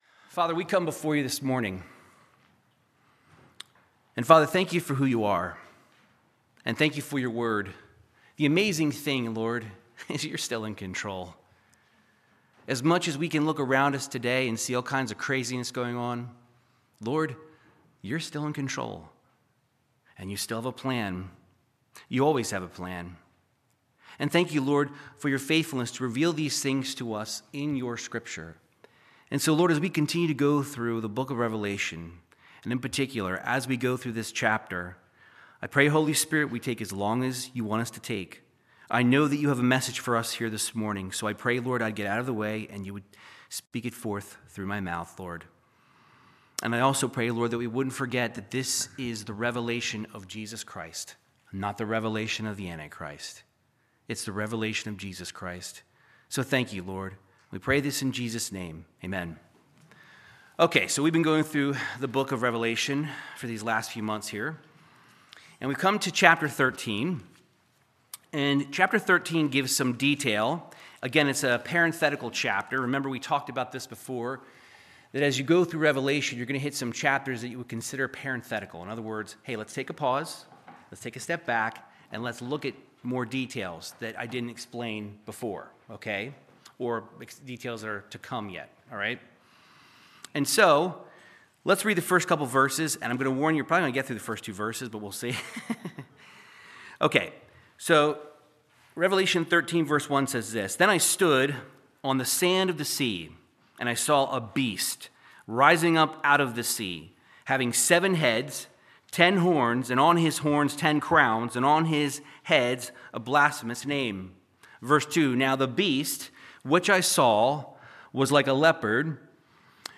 Verse by verse Bible Teaching through the Book of Revelation chapter 13